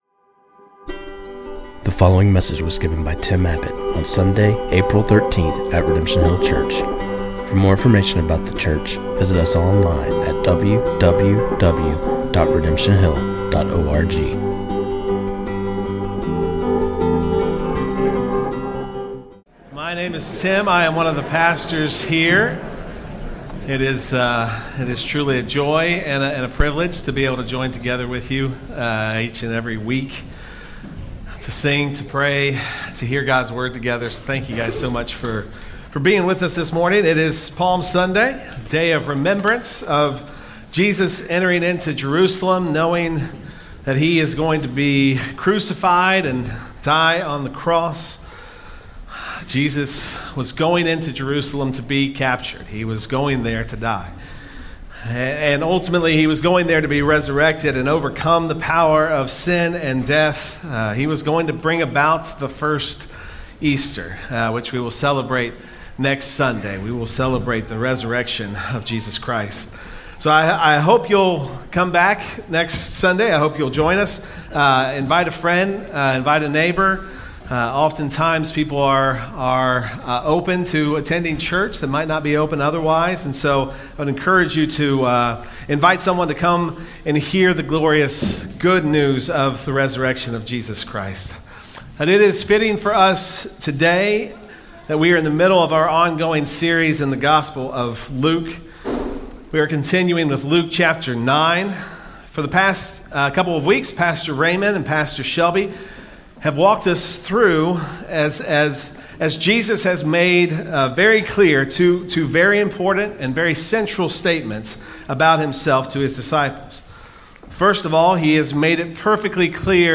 This sermon